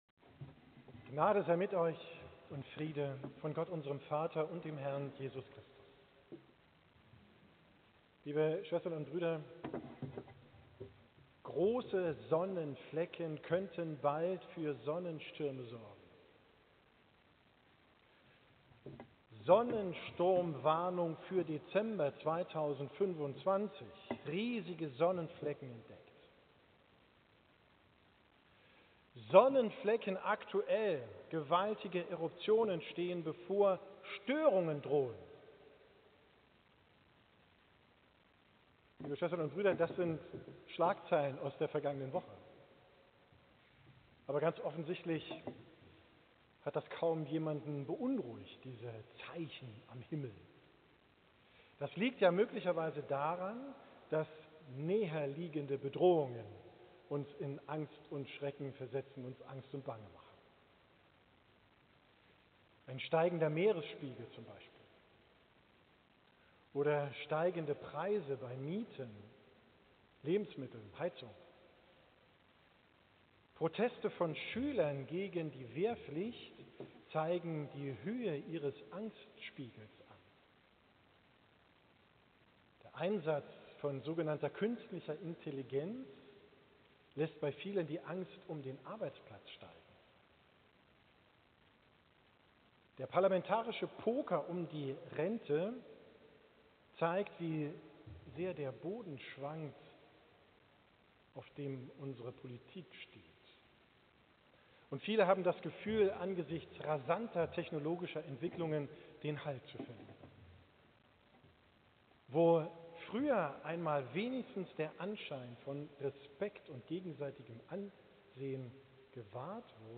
Predigt vom 2.